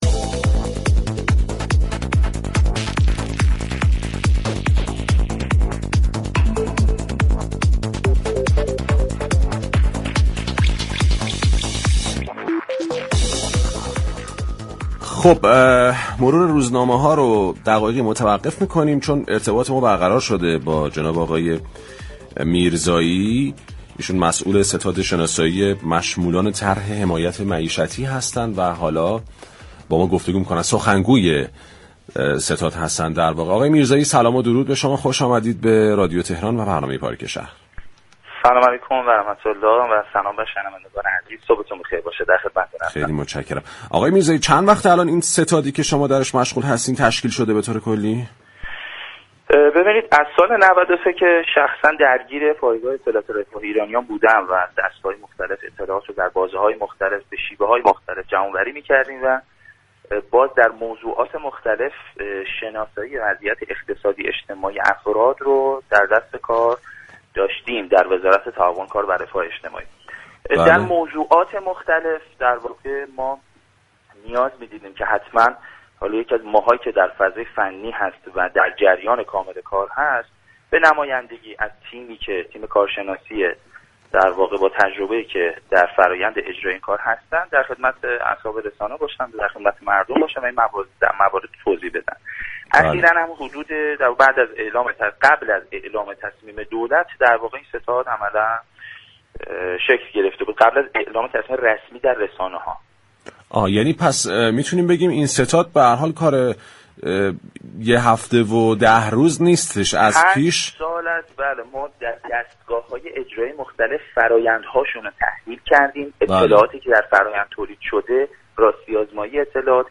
در گفتگو با پارك شهر